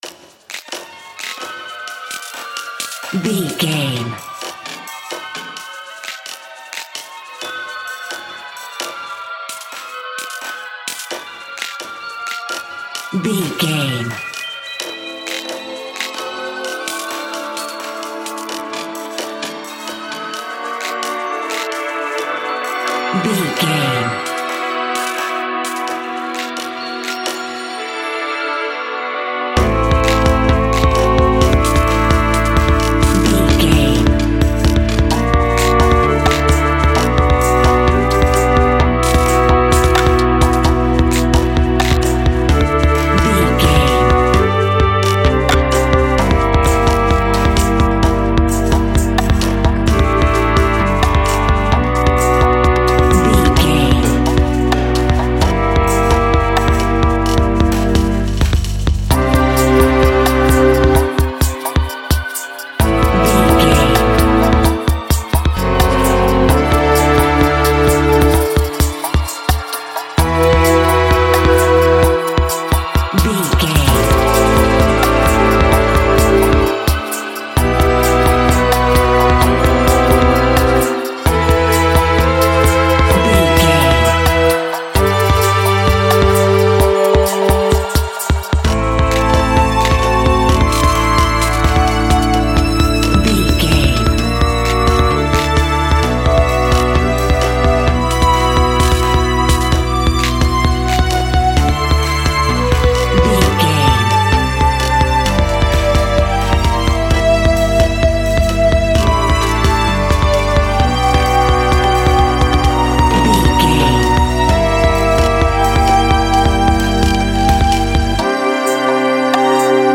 Fast
instrumental
Israeli music
Middle Eastern music
Hijaz scale
modal music
meditative
solemn
mystical
atmospheric
contemplative